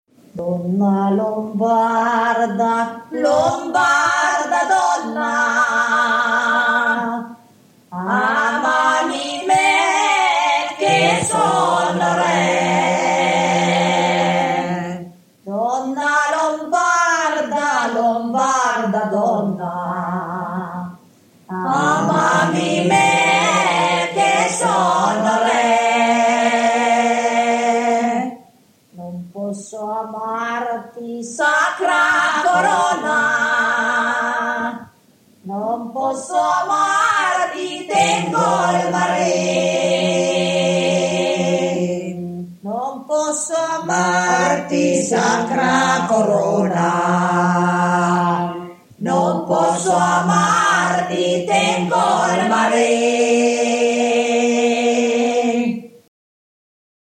Donna lombarda / [registrata a Trino Vercellese (VC), nel 1977, cantata dal Coro delle mondine di Trino Vercellese]
Esecutore: Coro delle mondine di Trino Vercellese